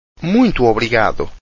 Muito obrigado/a   M[ng]wee[ng]tu Obrigahdu/a